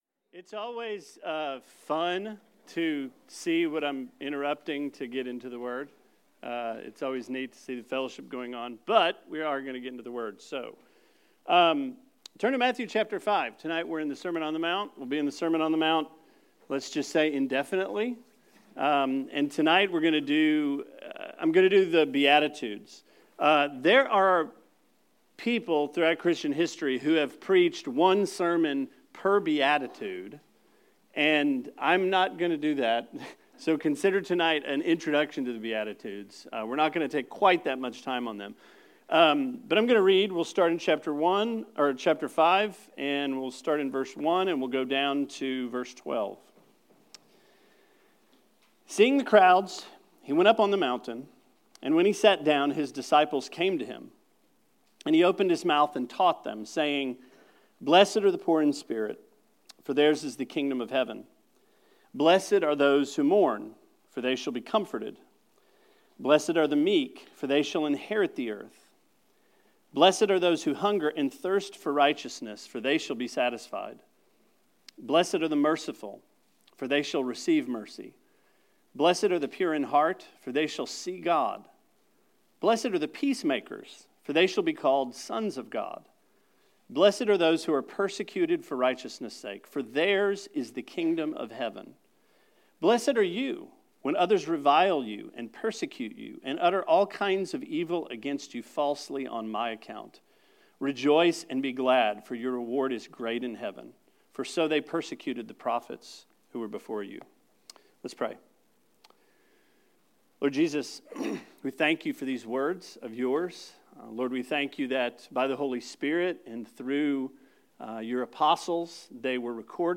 Sermon 01/17: Matthew - The Beatitudes